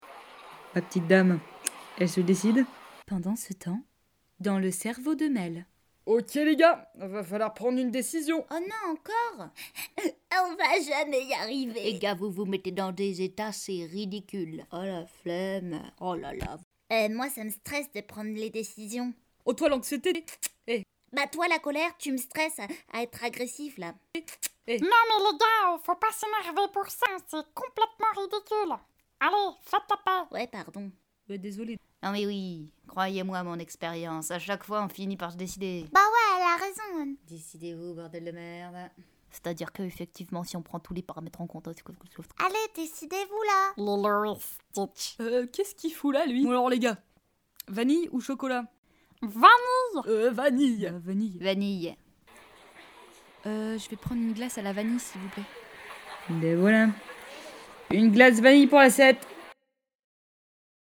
Voix off et dessins animés